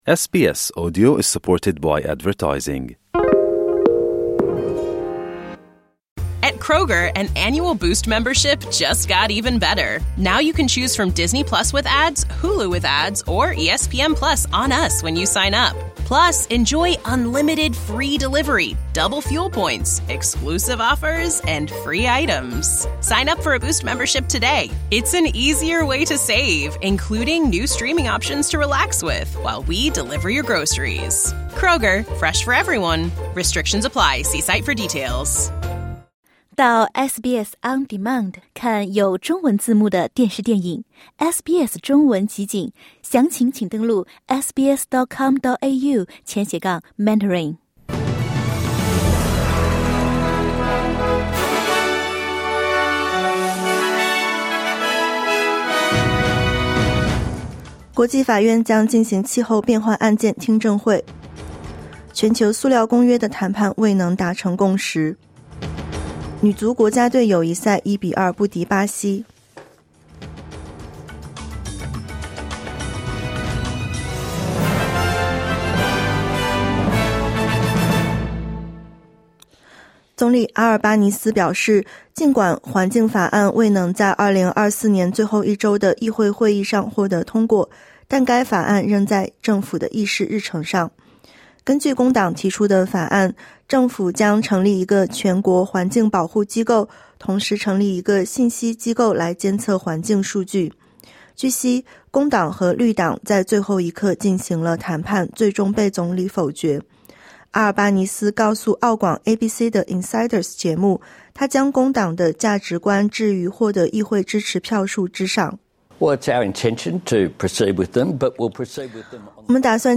SBS 早新闻（2024年12月2日）
SBS Mandarin morning news Source: Getty / Getty Images